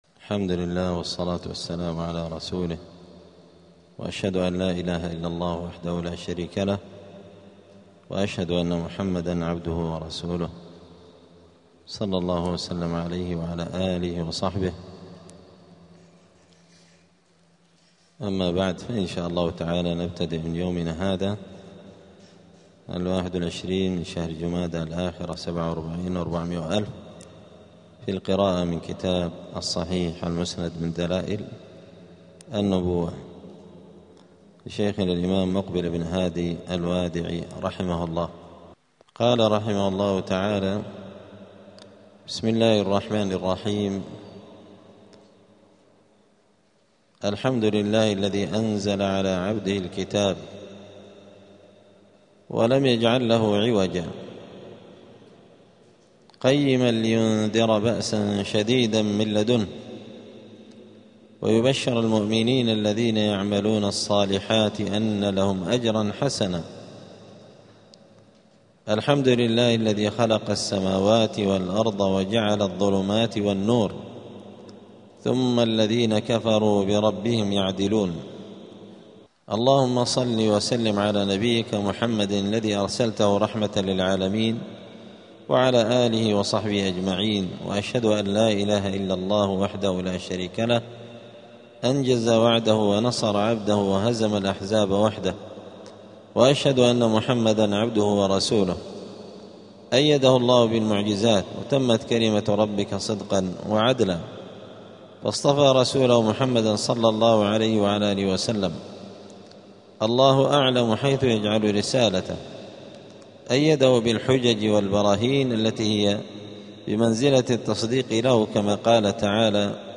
*الدرس الأول (1) {مقدمة المؤلف}.*
دار الحديث السلفية بمسجد الفرقان قشن المهرة اليمن